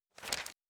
04_书店内_生死簿翻页.wav